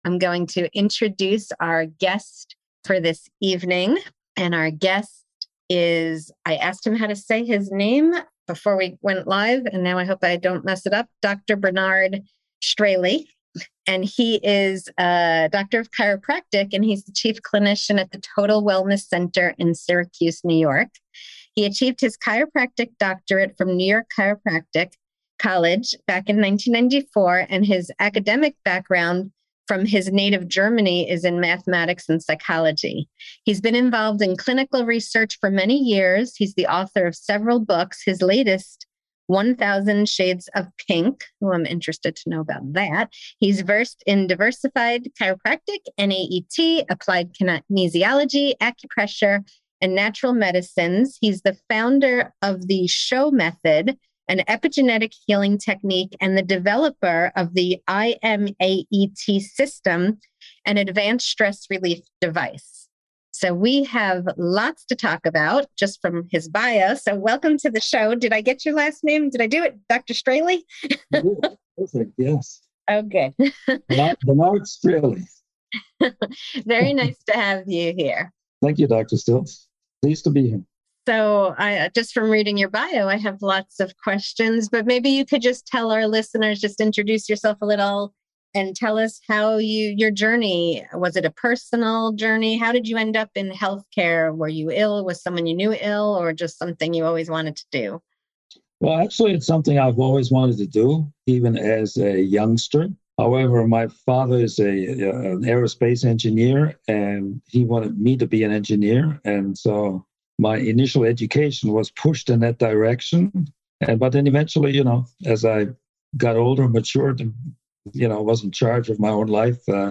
The Science of Self-Healing Podcast Interview